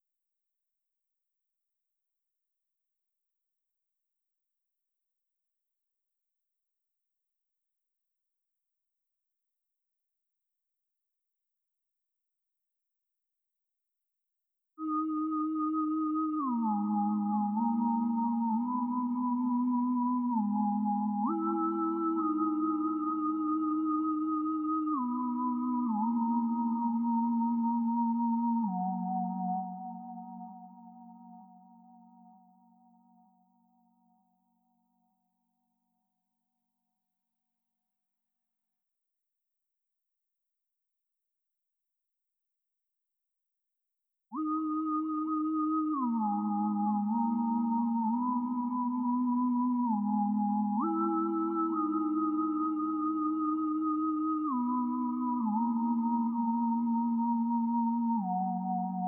Futurish Lead.wav